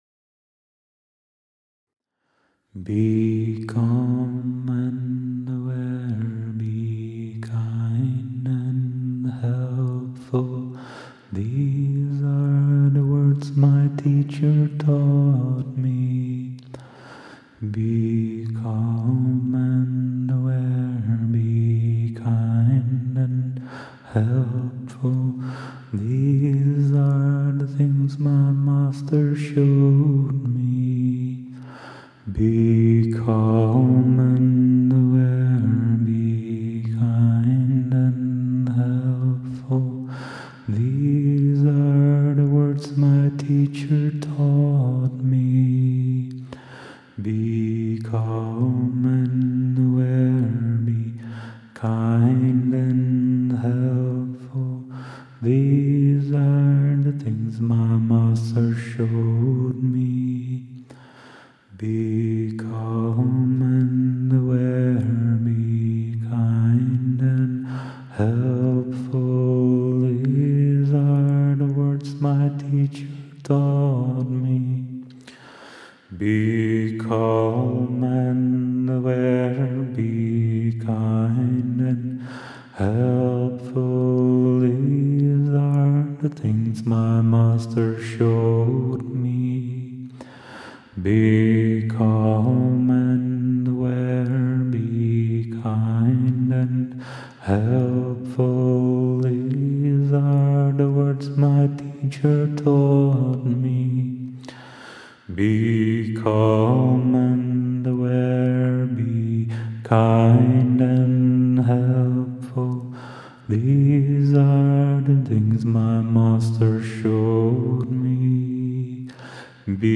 I hope that by chanting  these words my mind can also transform, and become more and more like the shining example of Rinpoche, who seems to embody the full depth of the meaning of these words in every moment and aspect of his life.